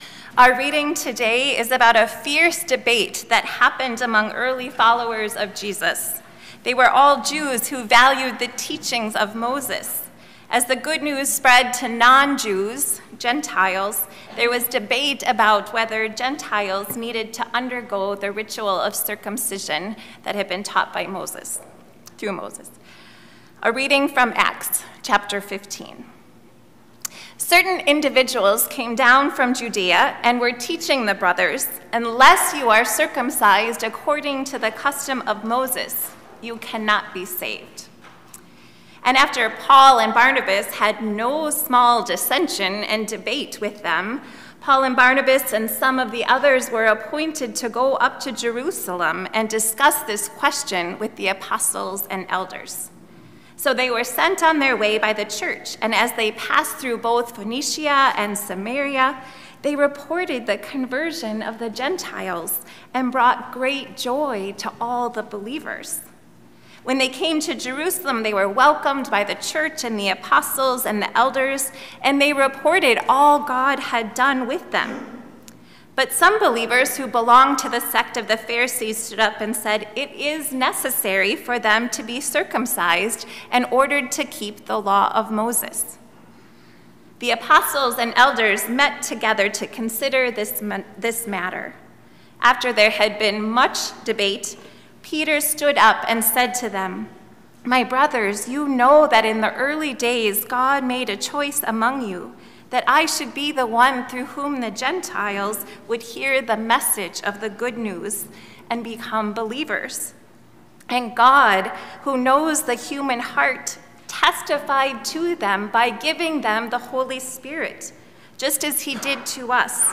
Sermons | Good Shepherd Lutheran Church